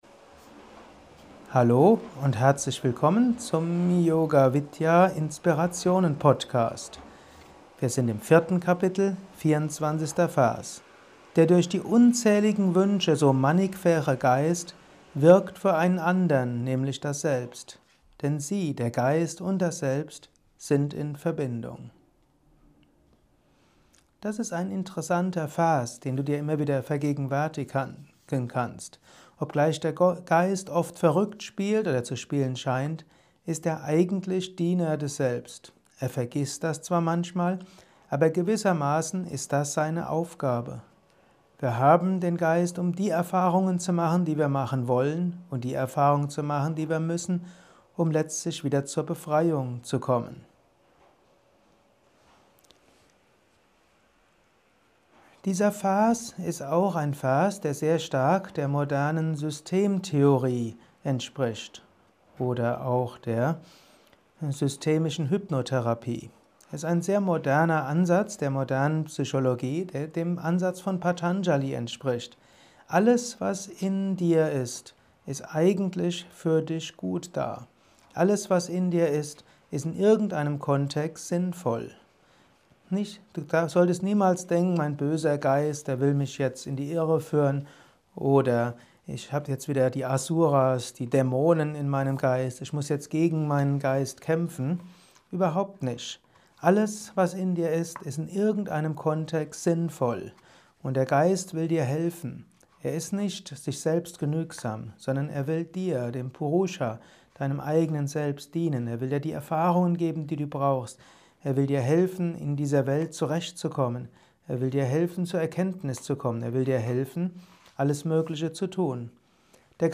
Jeden Tag ein 5-10 minütiger Vortrag über Yoga, Meditation und spirituelles Leben im Alltag.